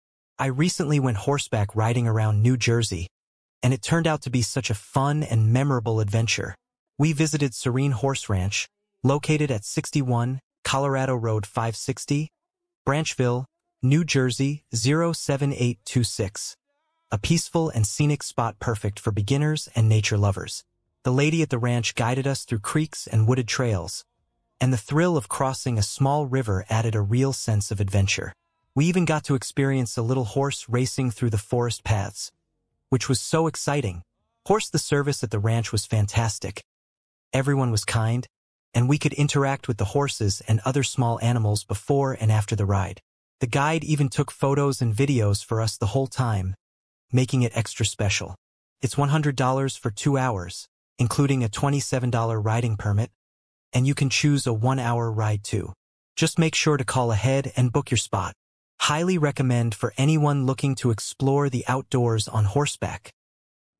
River Crossing + Trail Ride Fun